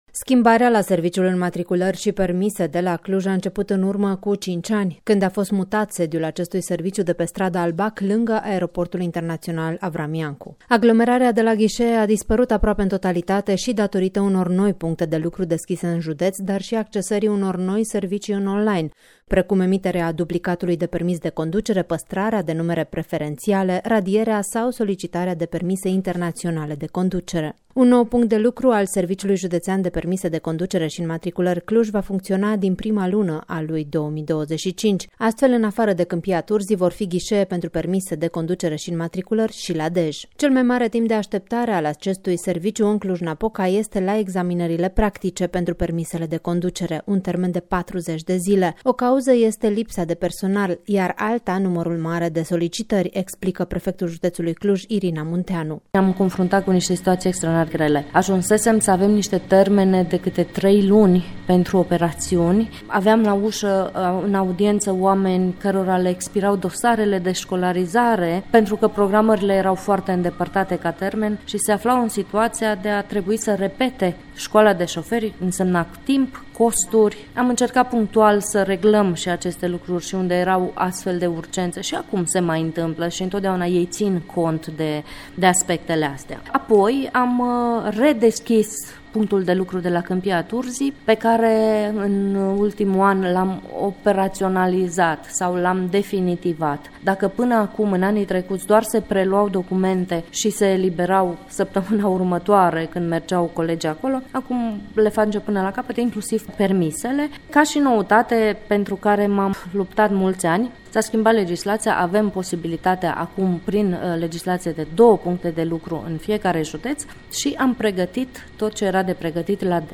O cauză este lipsa de personal, iar alta numărul mare de solicitări, explică prefectul județului Cluj, Irina Munteanu.